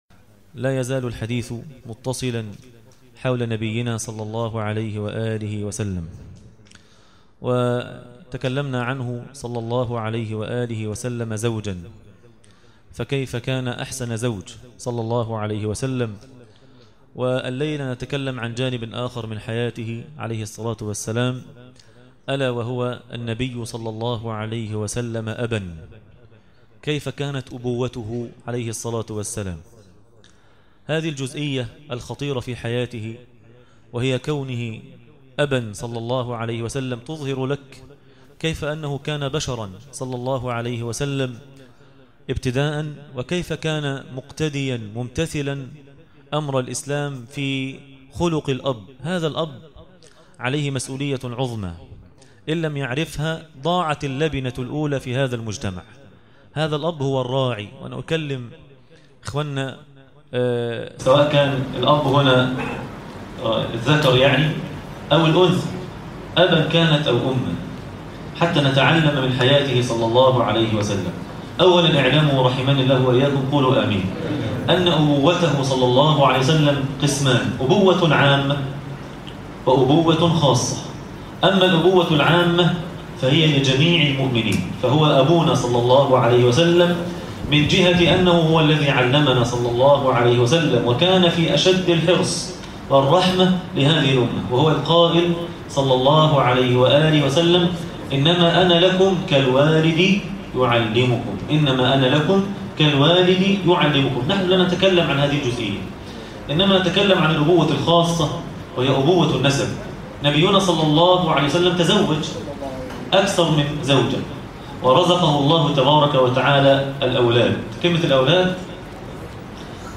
عنوان المادة النبي (صلي الله عليه وسلم ) أبا - درس التراويح ليلة 26 رمضان 1437هـ